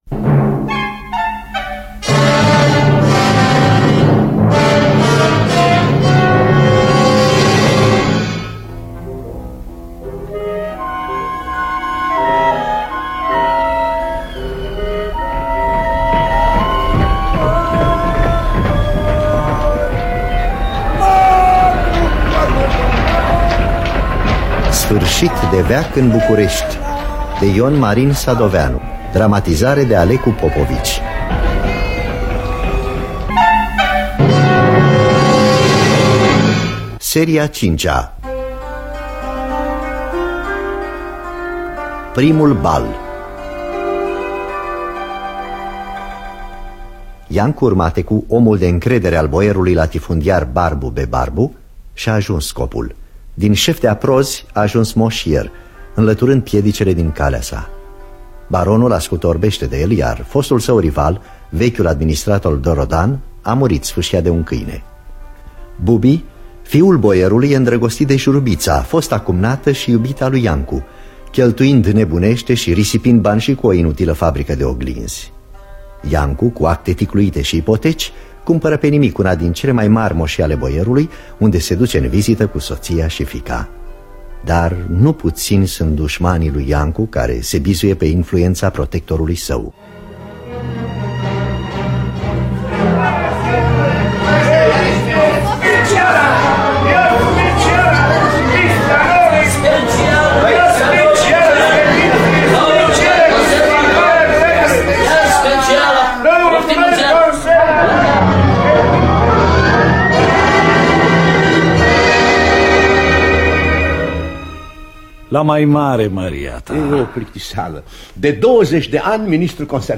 Dramatizarea radiofonică de Alecu Popovici.